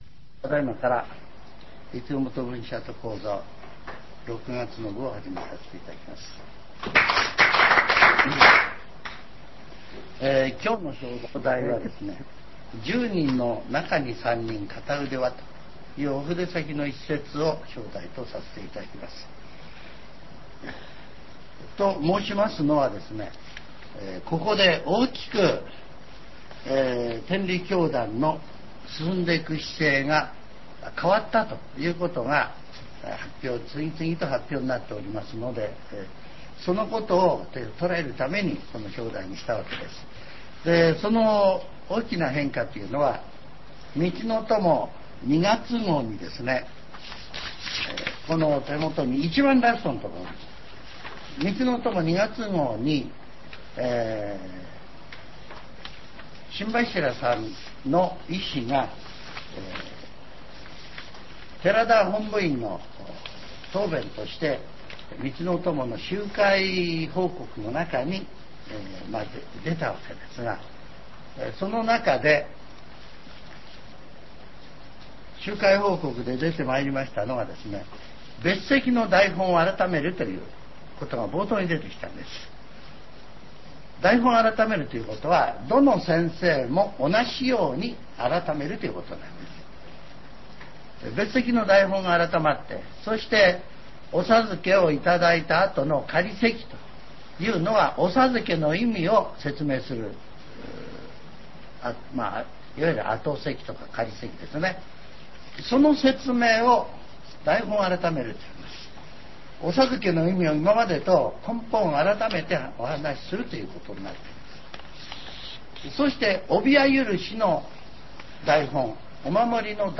全70曲中34曲目 ジャンル: Speech